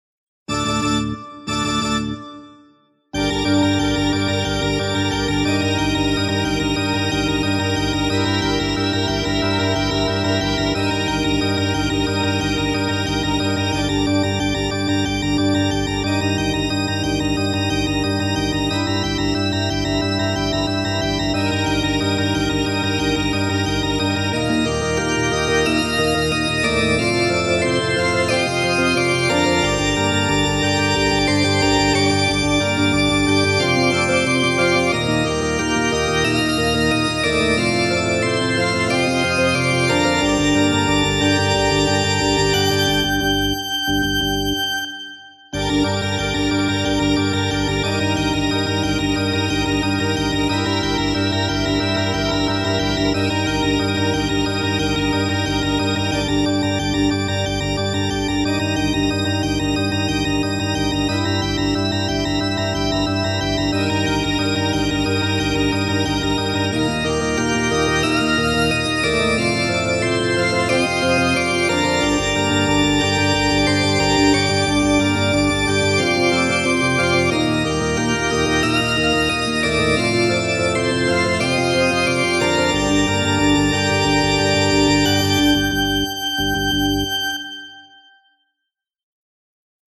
arrange tragique